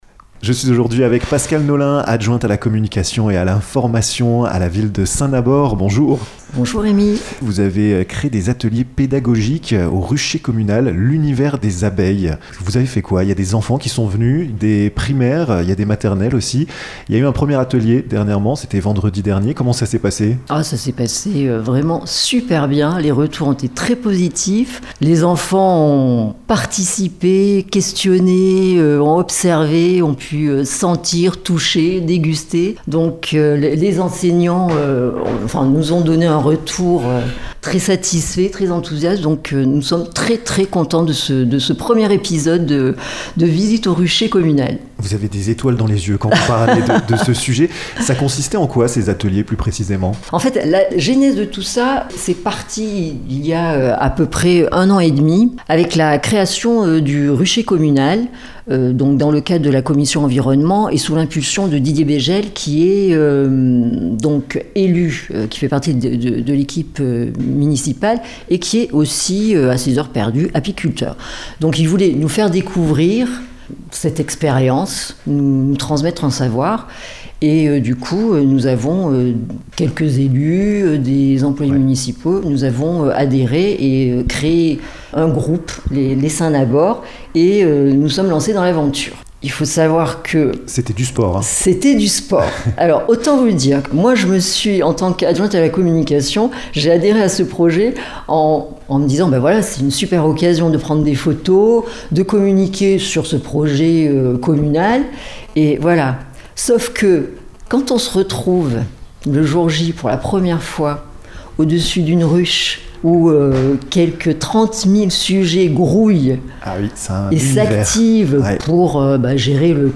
Pascale Naulin, adjointe à la communication et à l'informatio,n revient sur ce bel événement!